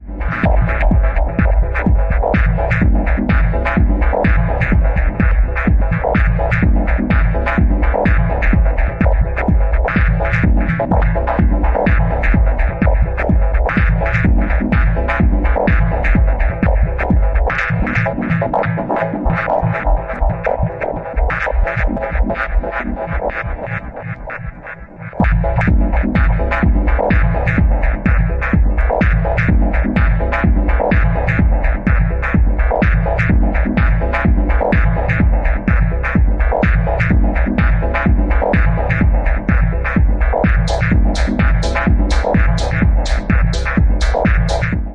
Pure & Raw Techno Trips!!